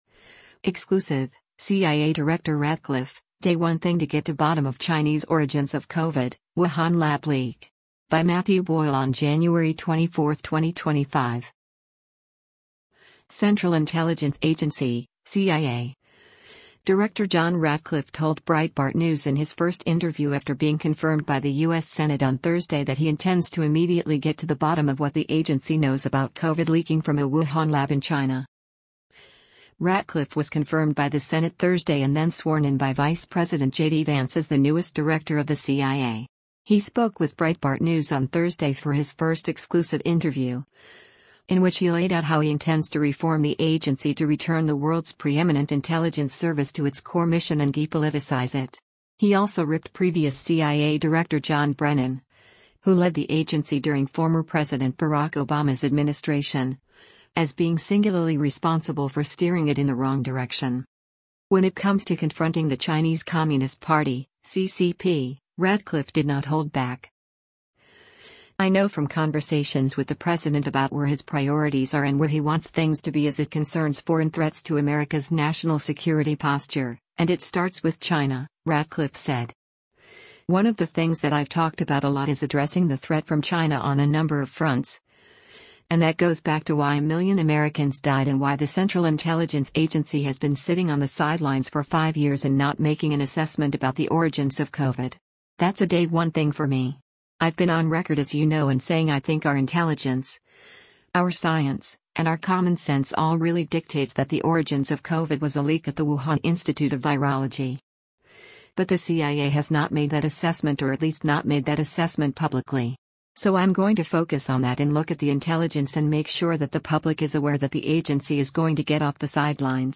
Central Intelligence Agency (CIA) director John Ratcliffe told Breitbart News in his first interview after being confirmed by the U.S. Senate on Thursday that he intends to immediately get to the bottom of what the Agency knows about COVID leaking from a Wuhan lab in China.